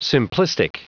Prononciation du mot simplistic en anglais (fichier audio)
Prononciation du mot : simplistic